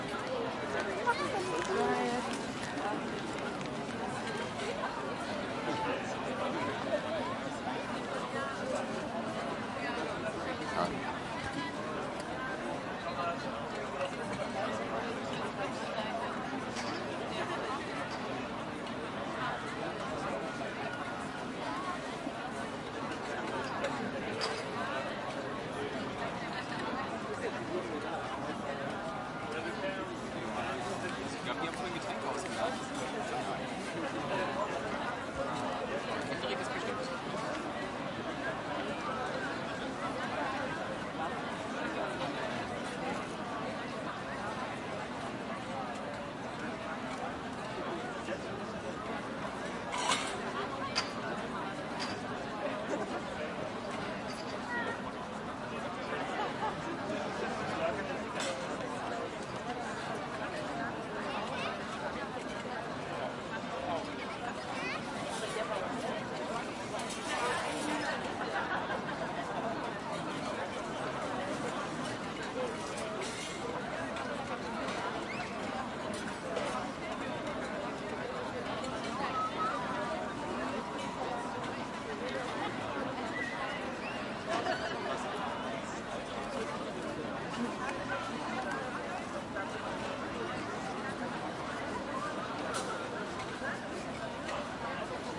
德国 " 户外社区节日人群广泛的中饭时间活跃的瓦拉德语和英语的声音吃勺子打金属
描述：户外社区节日人群ext中等膳食时间活跃walla德语和英语声音吃勺子打金属bowls4孩子玩更广泛的视角
Tag: 分机 社区 节日 人群 户外 沃拉